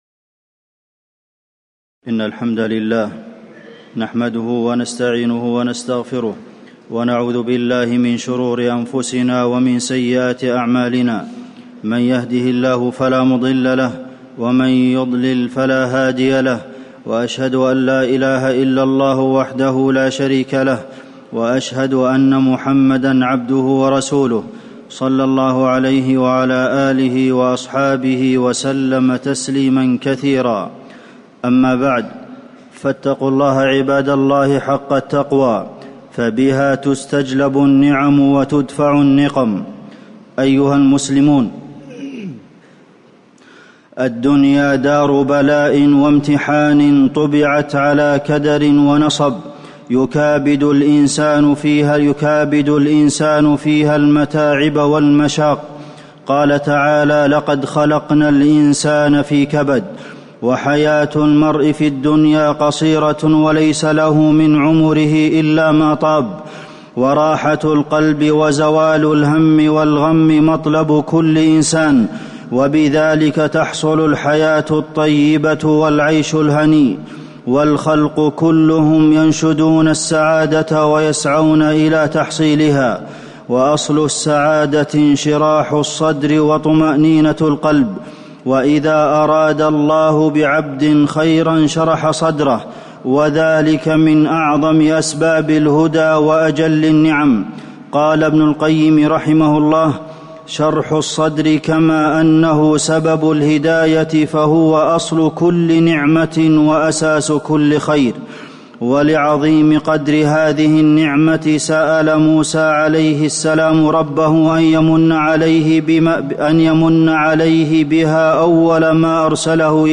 تاريخ النشر ٢٤ صفر ١٤٤٠ هـ المكان: المسجد النبوي الشيخ: فضيلة الشيخ د. عبدالمحسن بن محمد القاسم فضيلة الشيخ د. عبدالمحسن بن محمد القاسم انشراح الصدر